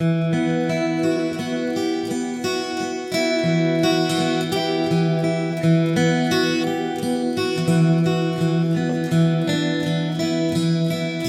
原声吉他系列2之4流行乐110
描述：这是一系列高质量的原声吉他循环，专门为Looperman制作。
Tag: 110 bpm Acoustic Loops Guitar Acoustic Loops 1.47 MB wav Key : Unknown